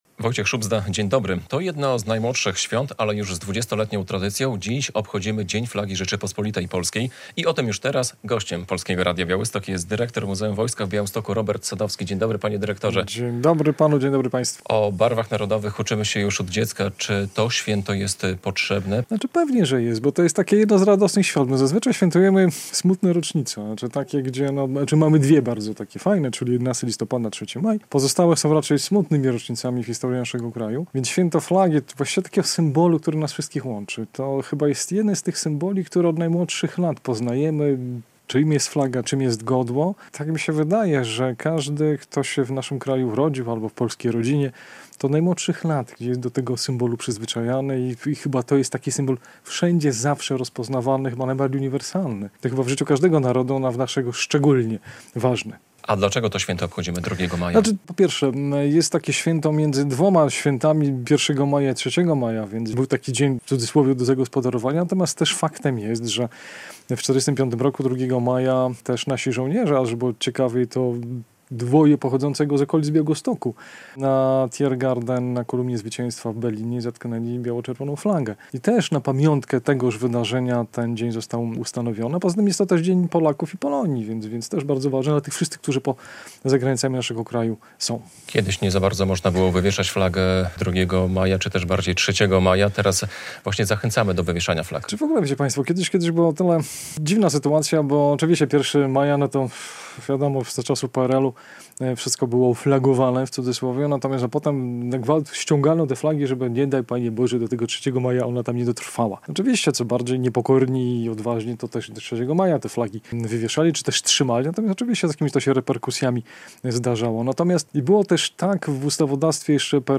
GOŚĆ RADIA BIAŁYSTOK